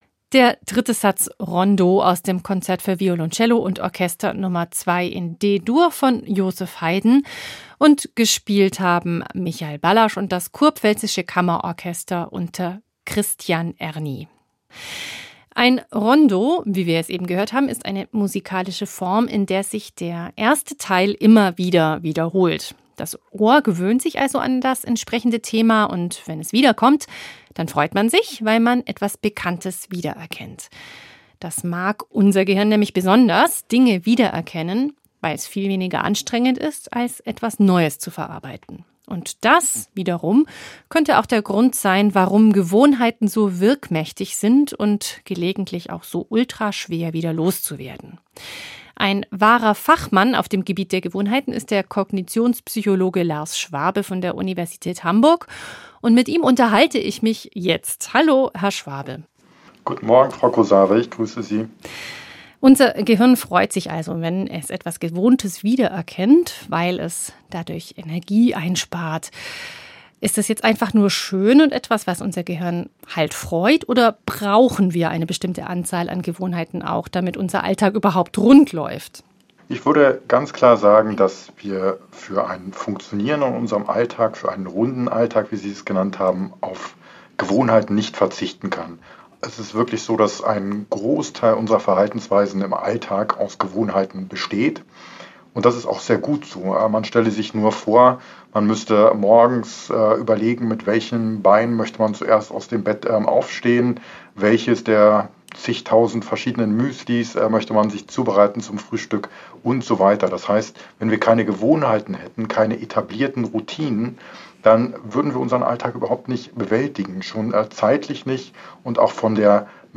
Das Interview führte